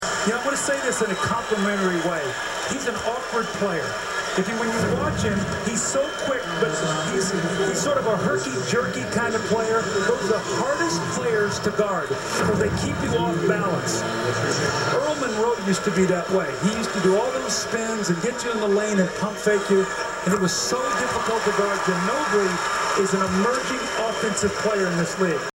A 2003 audio clip of NBA coach and television announcer, Doug Collins complimenting Ginobili's great play.